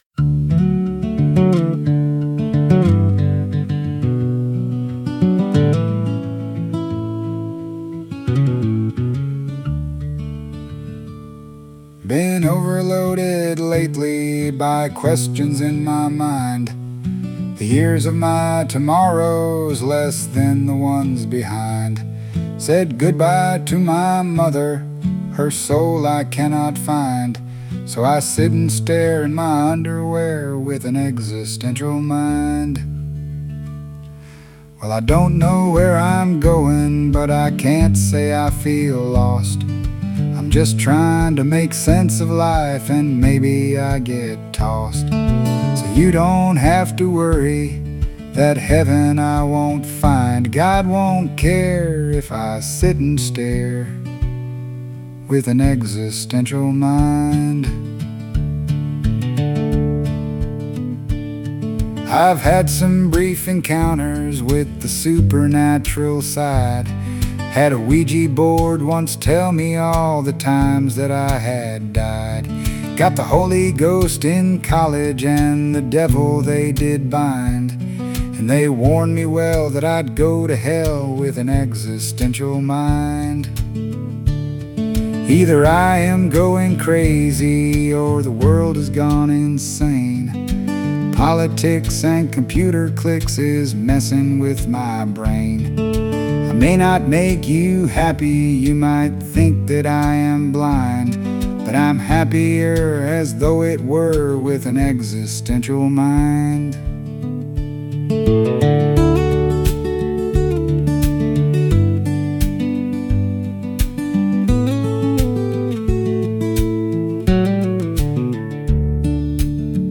on lead vocals.